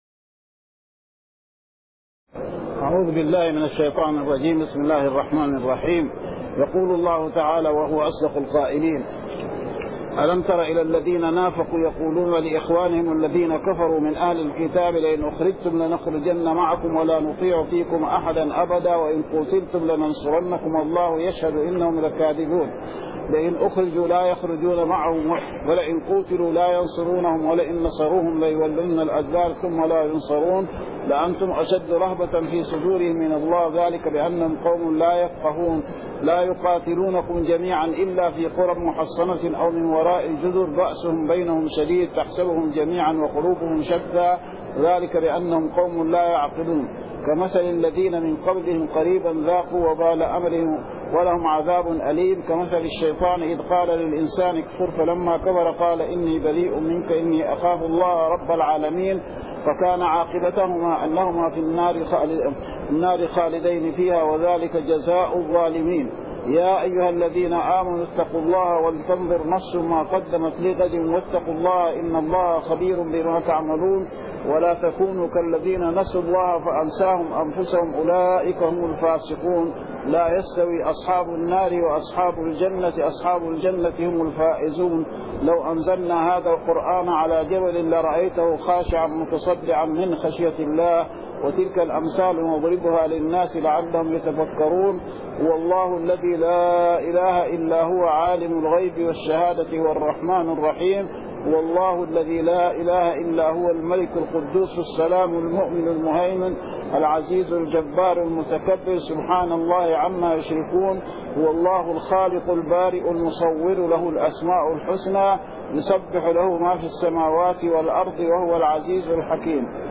من دروس الحرم المدنى الشريف تفسير الآيات 1-8 من سورة الحشر